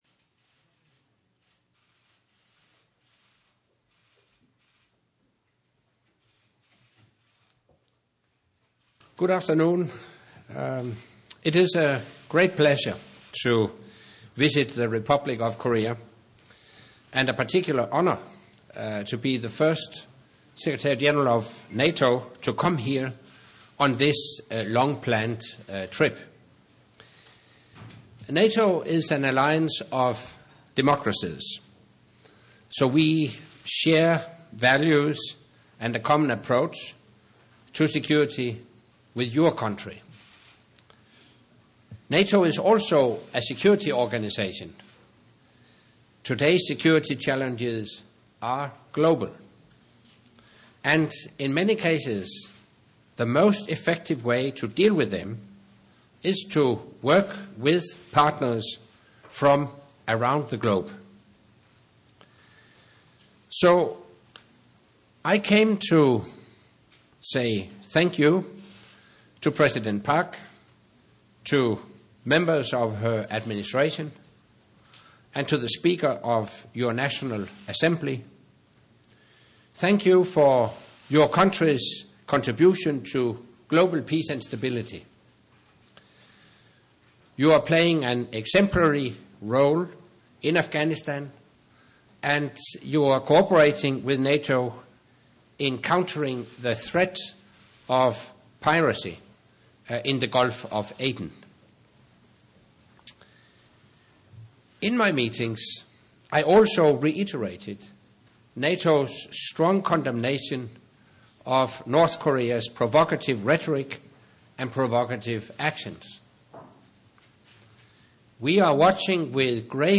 by NATO Secretary General Anders Fogh Rasmussen at the press conference held at the National Press Club in Seoul, Republic of Korea